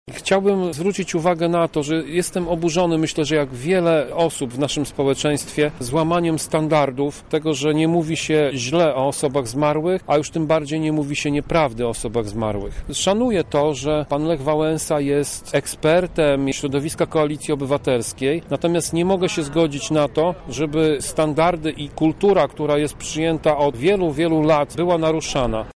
O tym, dlaczego słowa byłej głowy państwa nie powinny paść, mówi radny miasta Lublin Tomasz Pitucha: